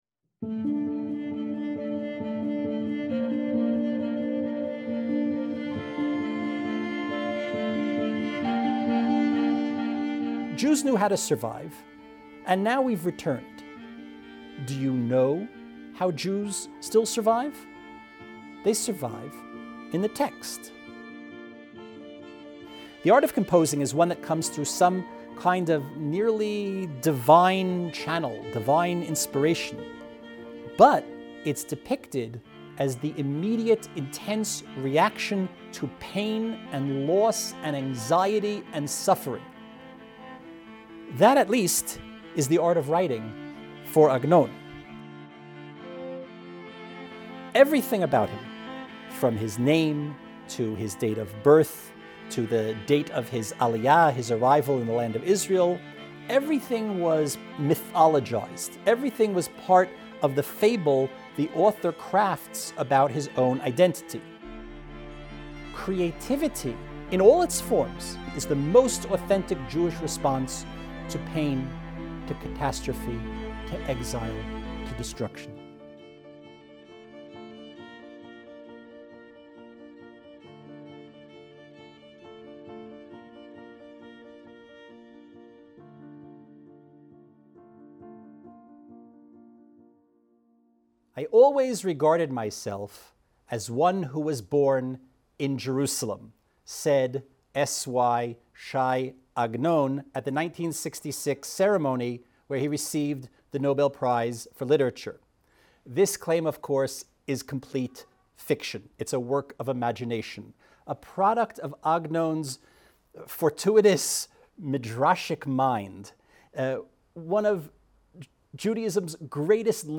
S.Y. Agnon was awarded the Nobel Prize for Jewish literature in 1966, the first—and to this day only—Hebrew writer to win the award. In this first lecture